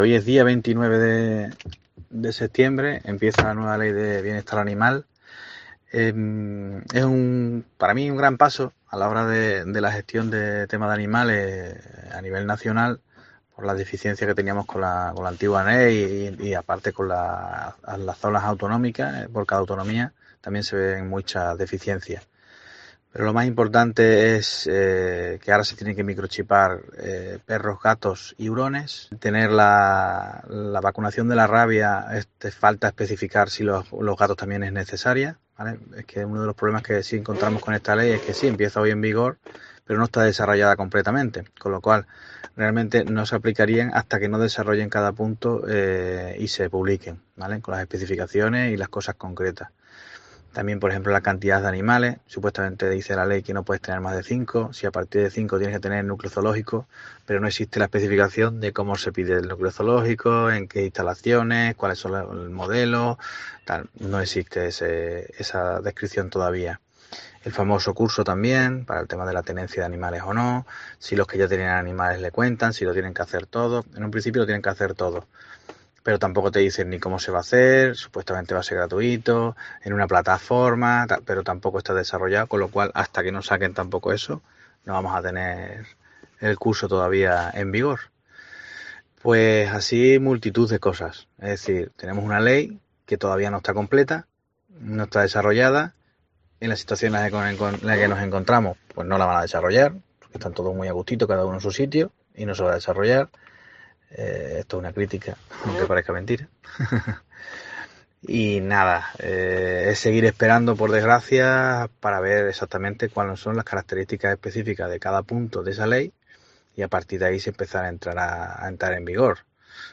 Boletines COPE